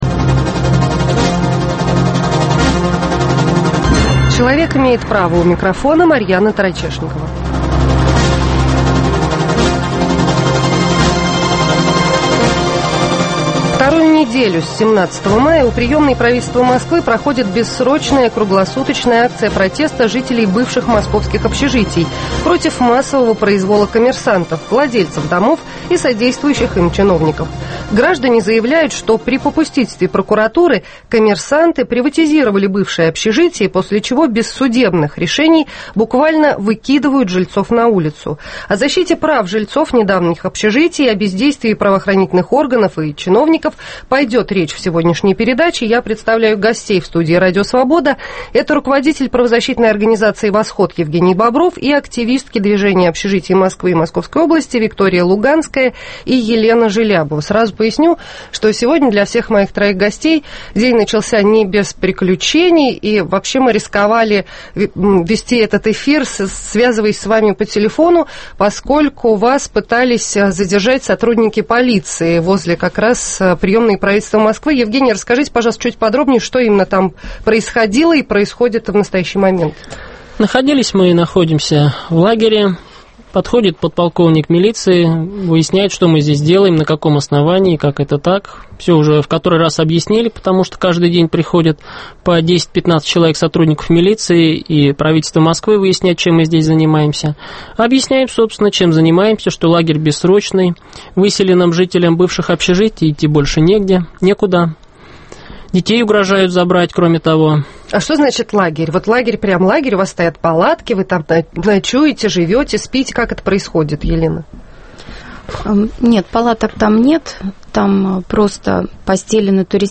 В студии Радио Свобода